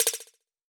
Percussion Echo Notification3.wav